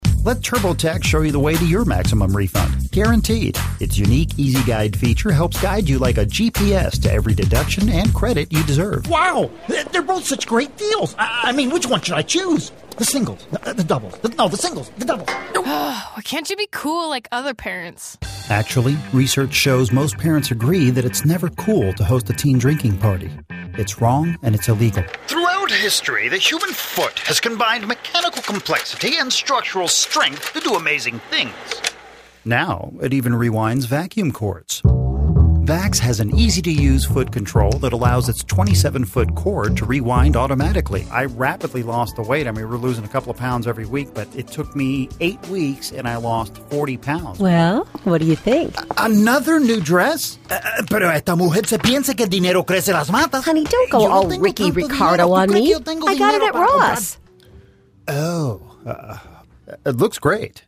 A versatile Voice Actor who takes direction well and can also deliver a variety of unique character voices.
Conversational, guy next door, humorous, enthusiastic, character, youthful, caring.
Sprechprobe: Werbung (Muttersprache):